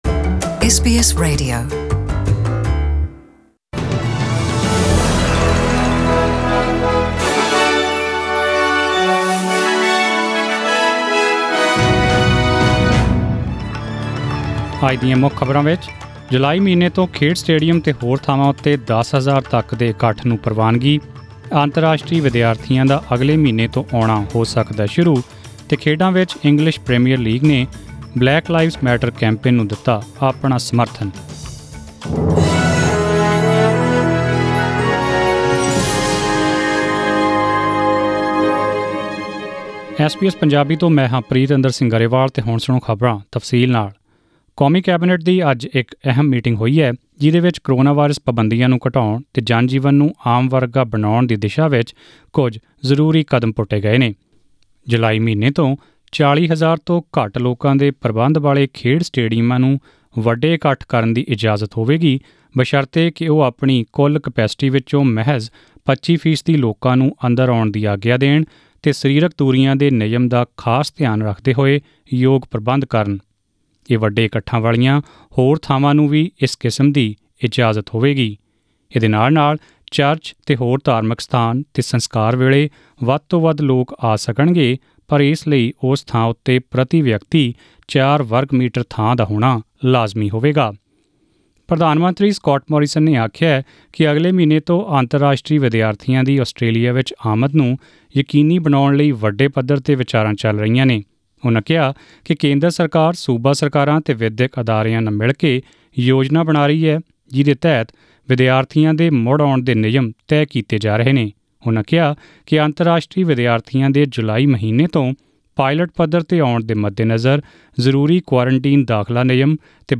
Australian News in Punjabi: 12 June 2020